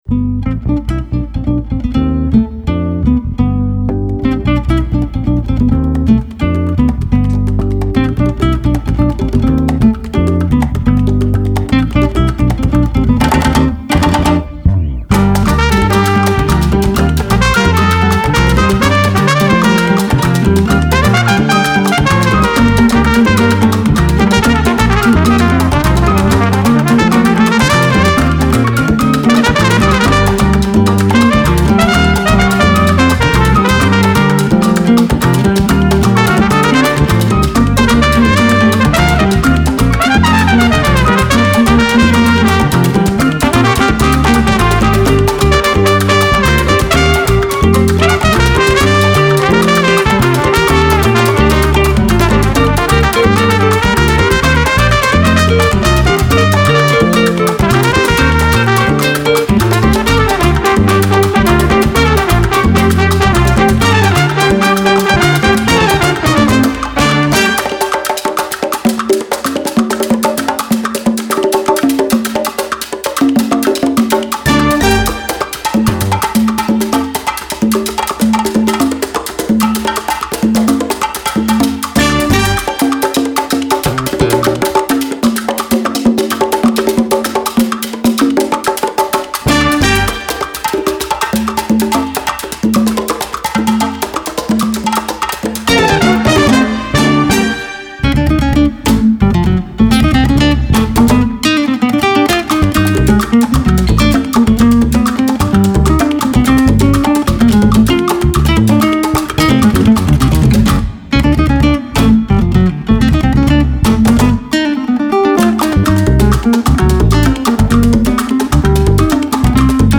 BRESIL / COLOMBIE
bongos, perc
congas, vibraphone, perc